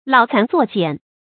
老蠶作繭 注音： ㄌㄠˇ ㄘㄢˊ ㄗㄨㄛˋ ㄐㄧㄢˇ 讀音讀法： 意思解釋： 老蠶吐絲作繭，把自己包在里面。比喻自己束縛自己。